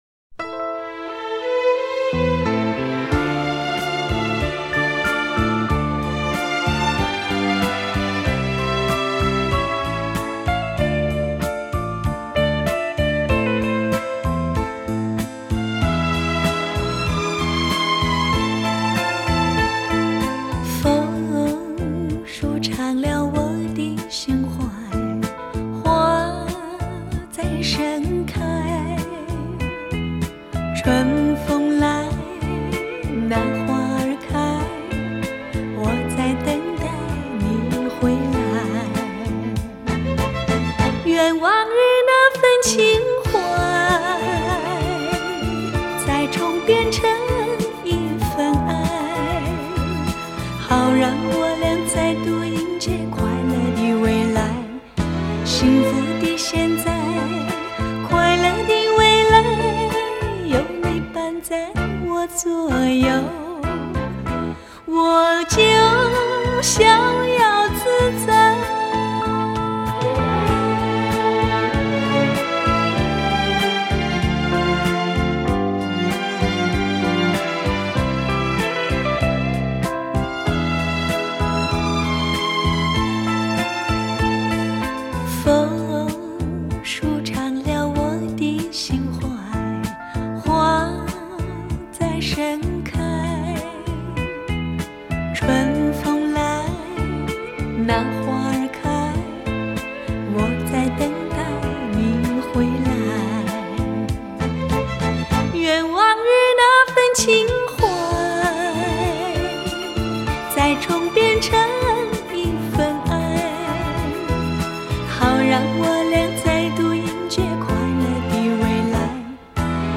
以当今至高规格音效处理